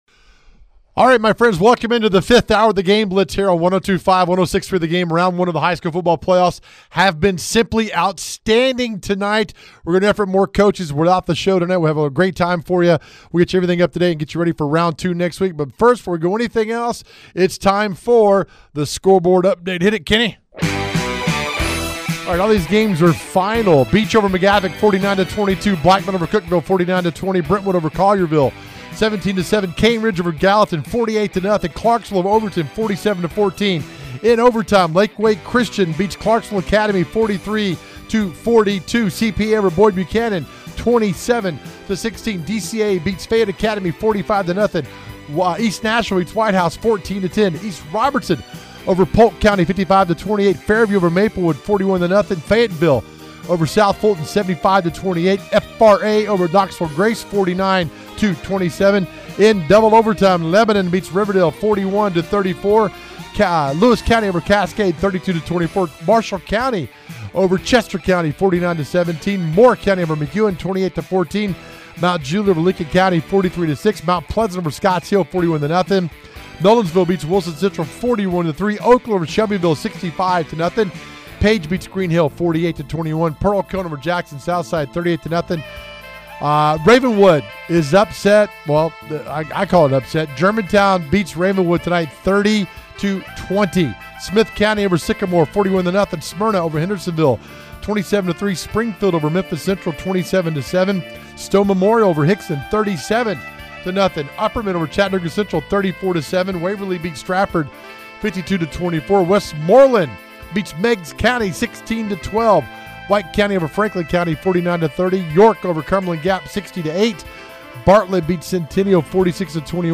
Its playoff time and we have full coverage for you right here with coaches interviews and live game reports as it happens!